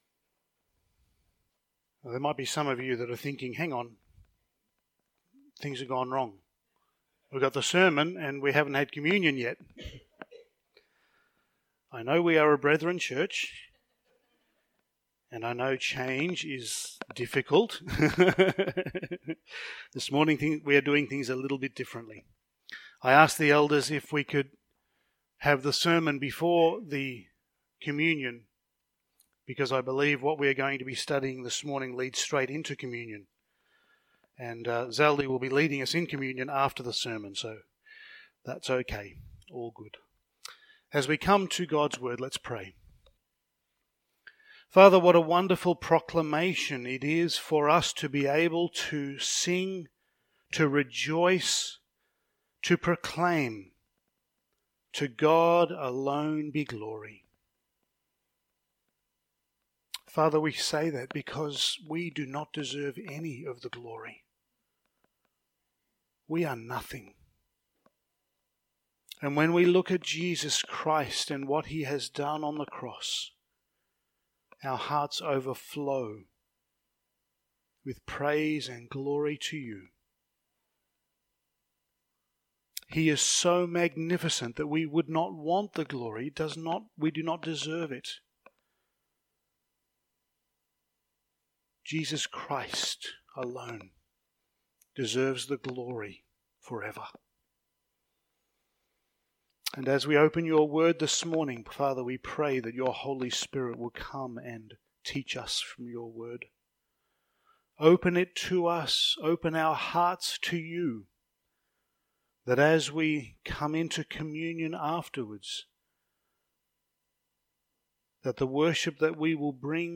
Passage: 2 Samuel 21:1-14 Service Type: Sunday Morning